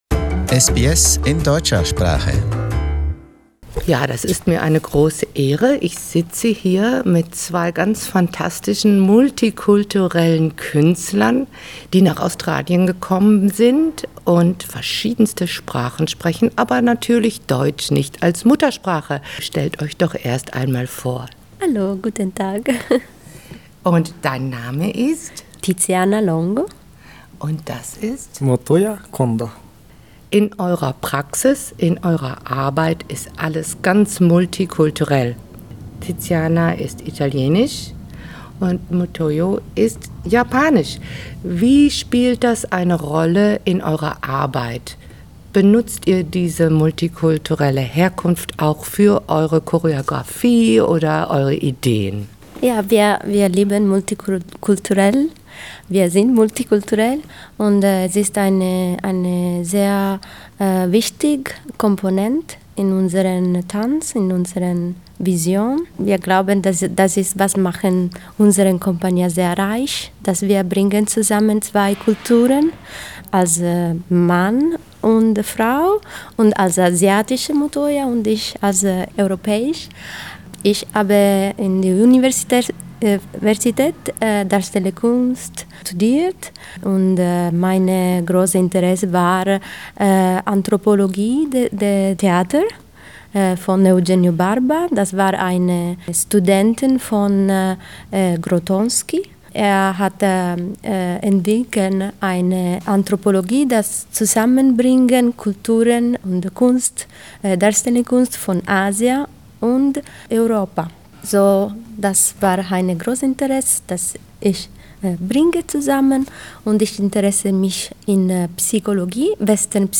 In conversation: Motimaru